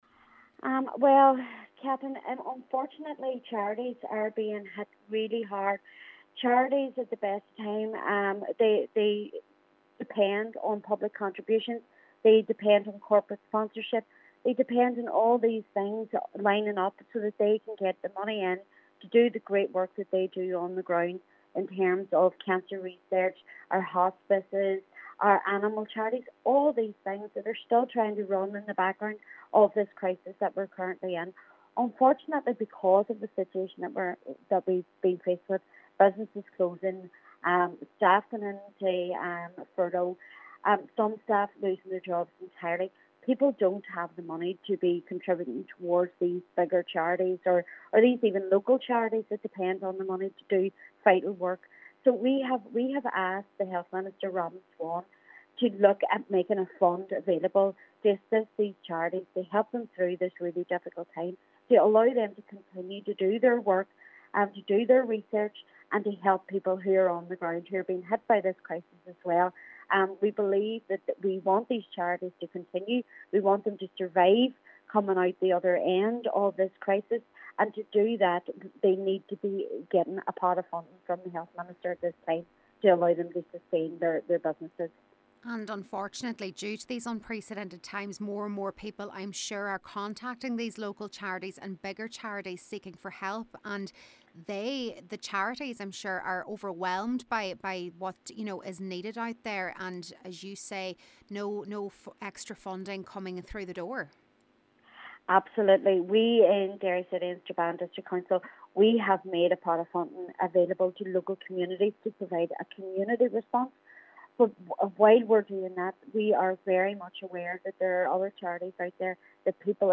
Sinn Féin Health spokesperson Councillor Sandra Duffy says the Department must help them in their hour of need: